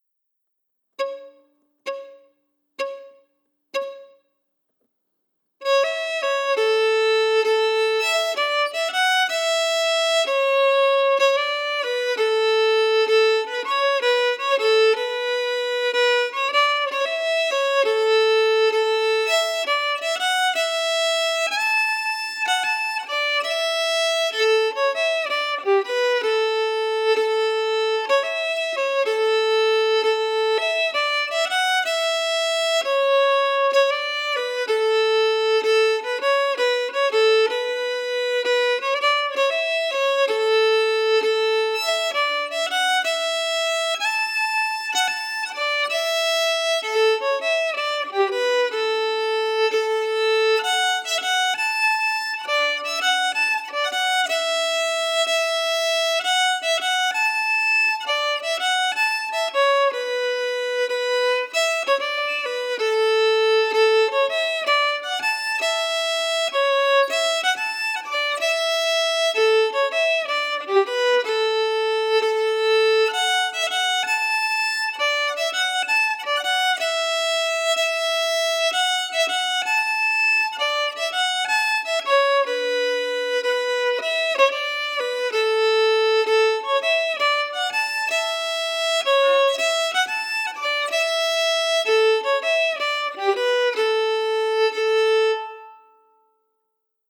Key: A Mixolydian
Form: Retreat March (9/8 time)
Slow for learning